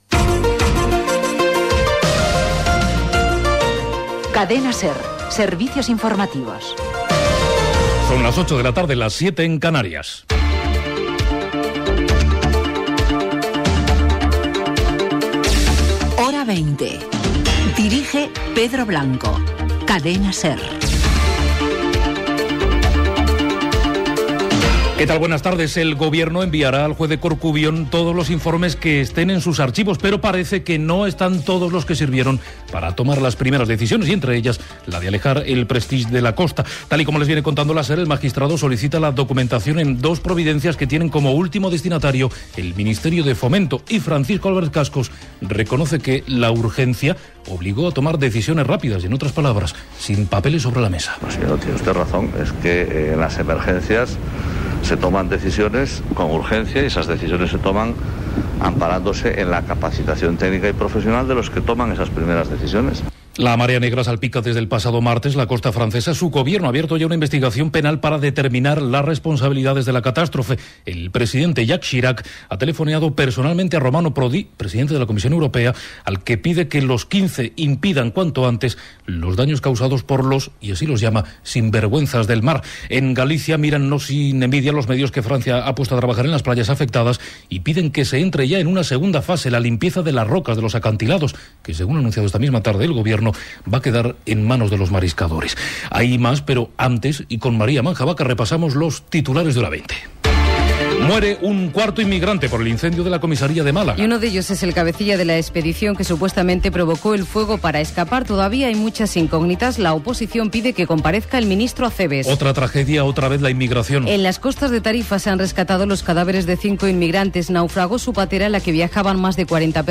Gènere radiofònic Informatiu